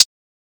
edm-hihat-60.wav